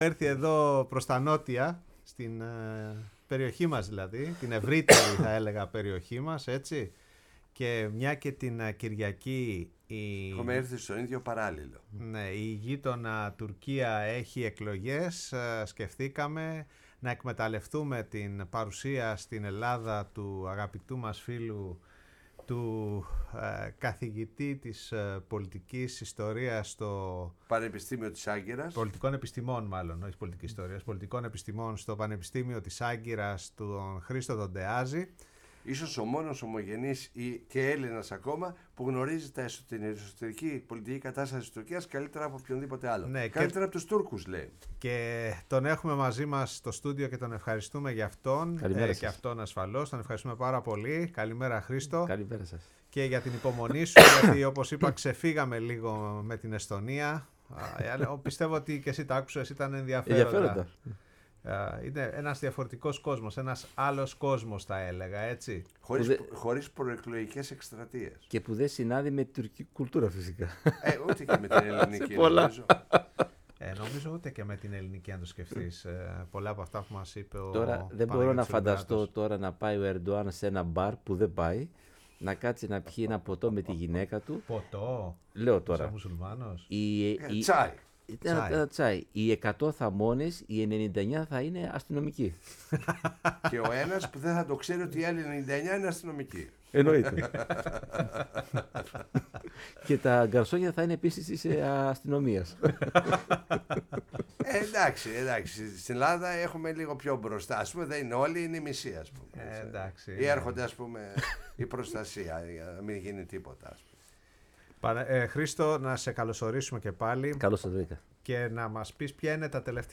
Μιλώντας απο το στούντιο της Φωνής της Ελλάδας